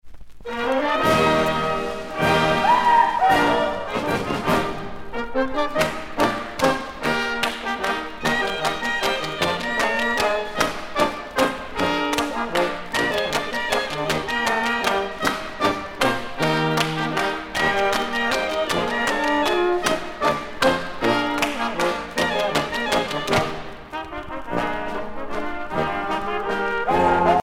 danse : ländler
Pièce musicale éditée